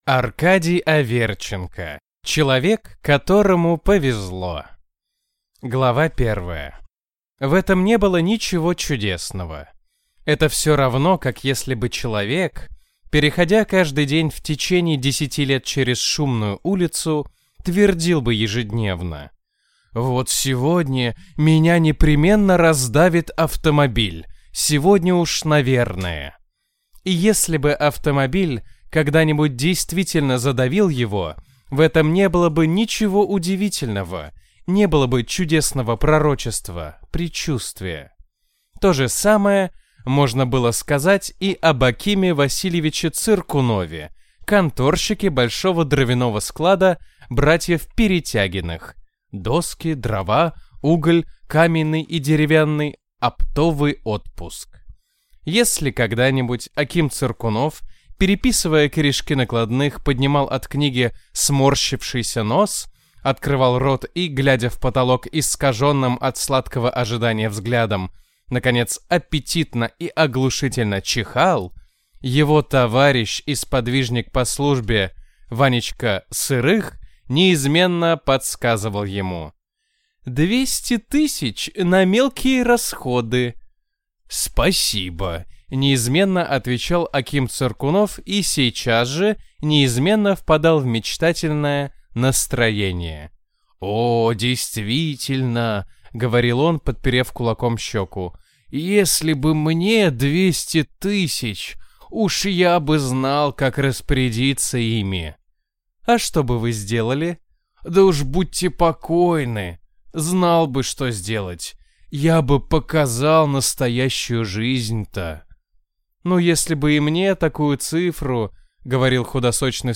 Аудиокнига Человек, которому повезло | Библиотека аудиокниг